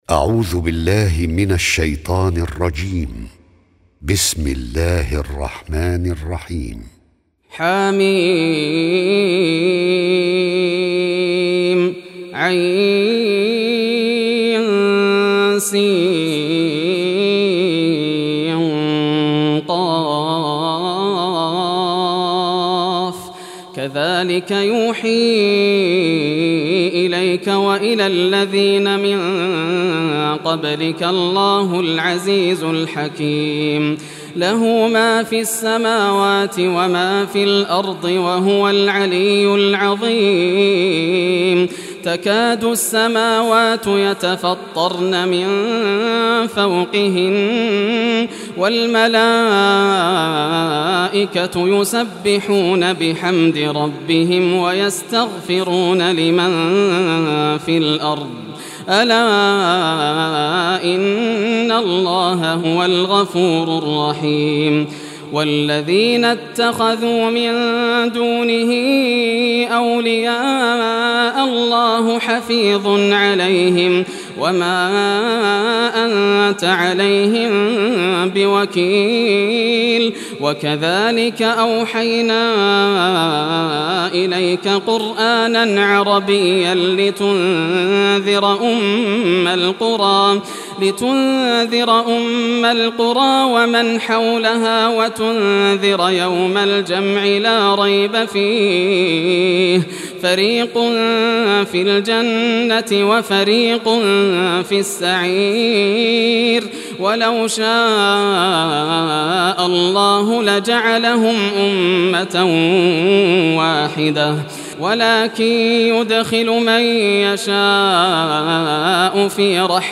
Surah Ash-Shuraa Recitation by Yasser al Dosari
Surah Ash-Shuraa, listen or play online mp3 tilawat / recitation in Arabic in the beautiful voice of Sheikh Yasser al Dosari.